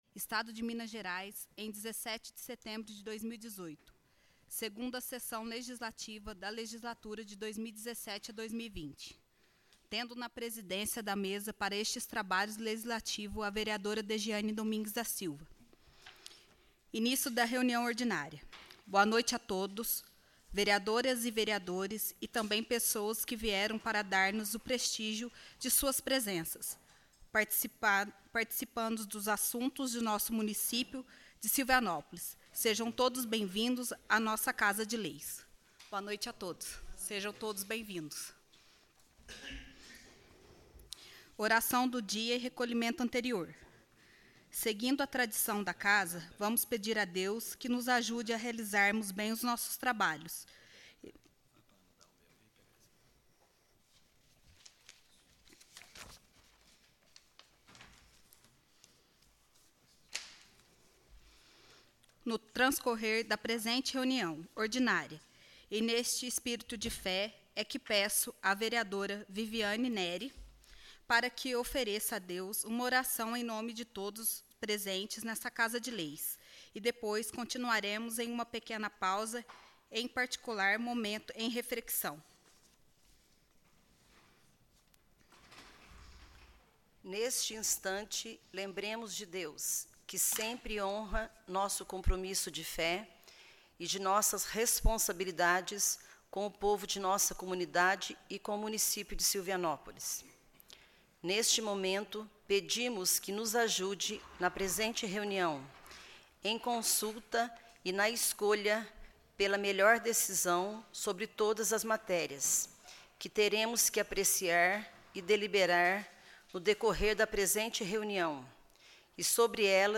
Áudio da 28ª Reunião Ordinária - Parte 1